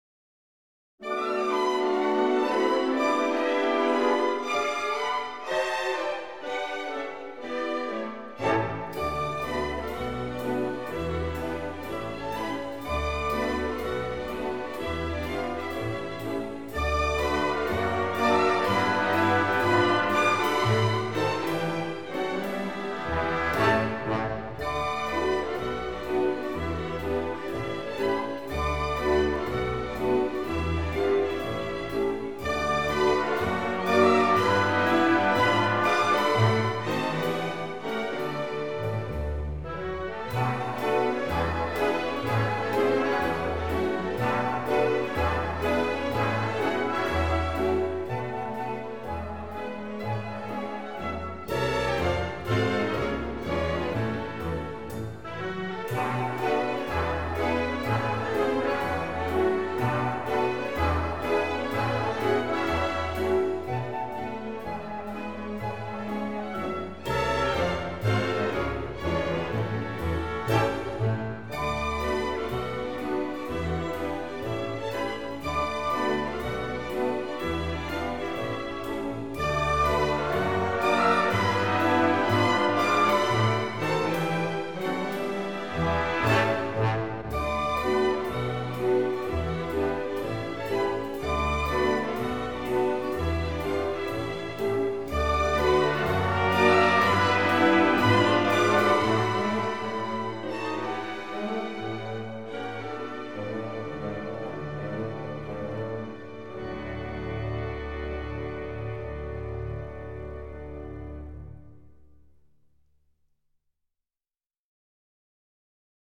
Jazz Legato